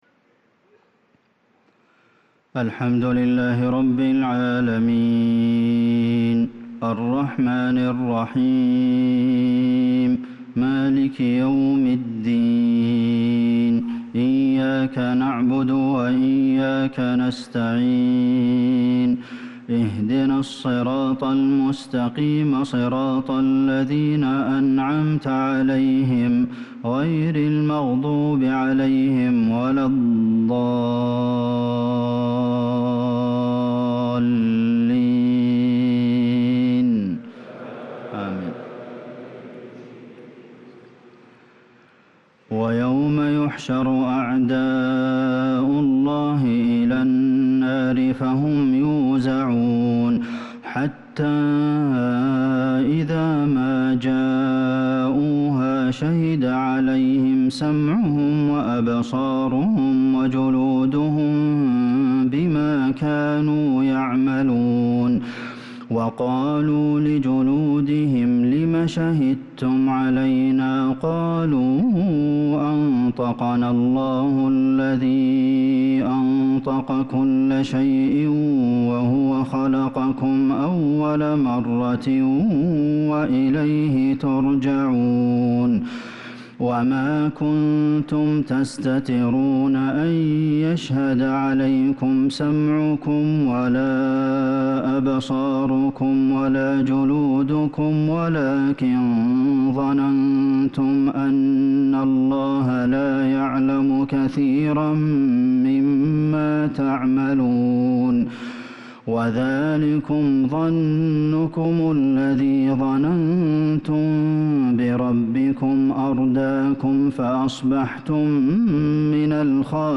صلاة العشاء للقارئ عبدالمحسن القاسم 16 ذو الحجة 1443 هـ